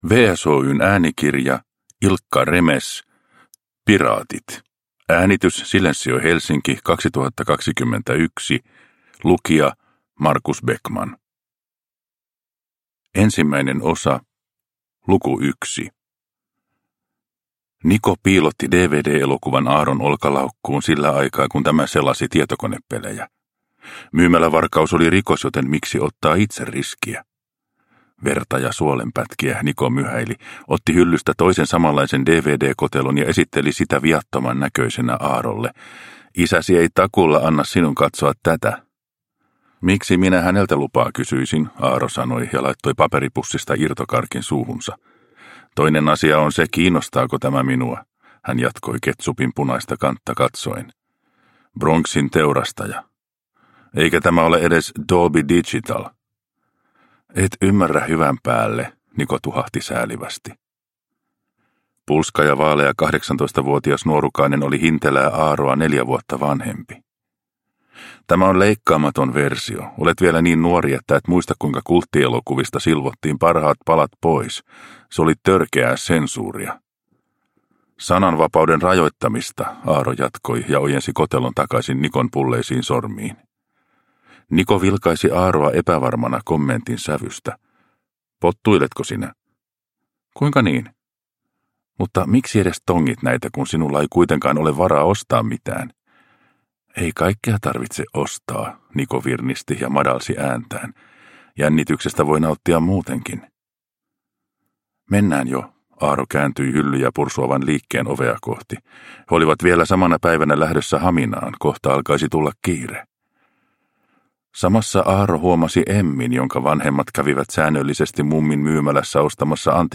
Piraatit – Ljudbok – Laddas ner